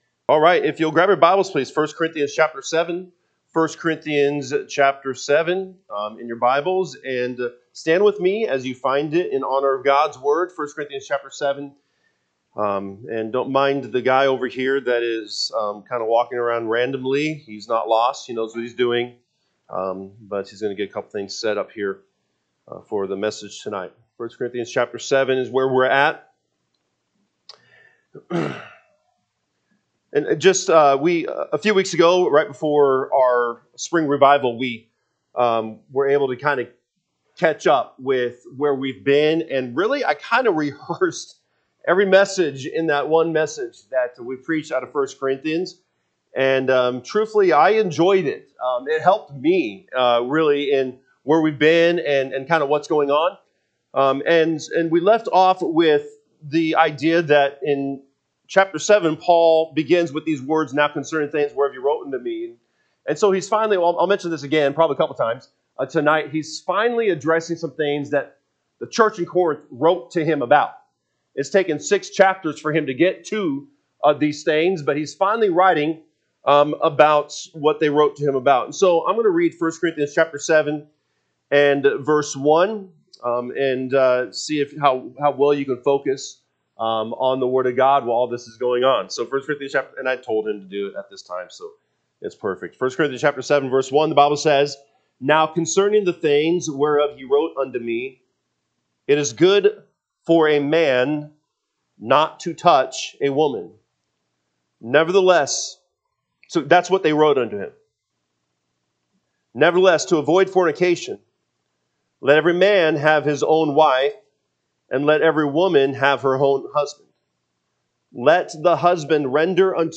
Sunday PM Message